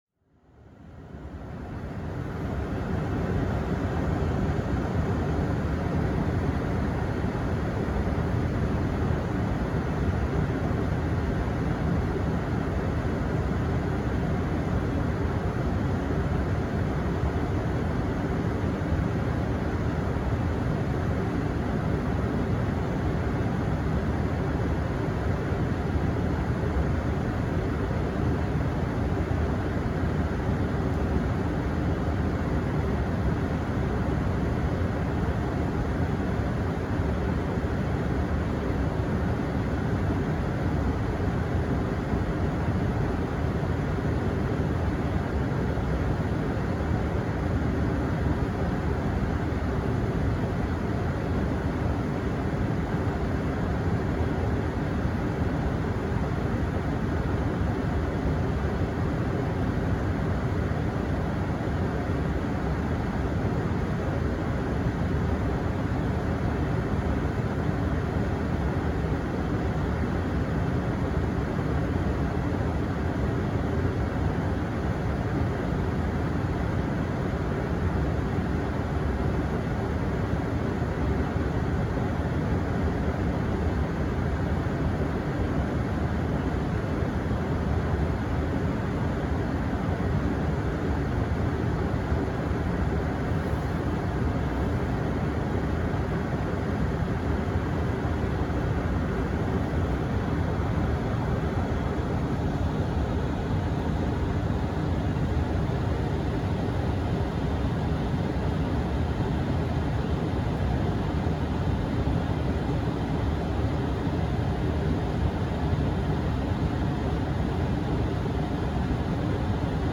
Звуки печки в машине
На этой странице собрана коллекция реалистичных звуков работающей печки в автомобиле. Эти уютные и монотонные шумы идеально подходят для расслабления, медитации, концентрации на работе или в качестве фонового звука для спокойного сна.
Шум печки автомобиля и разморозка лобового стекла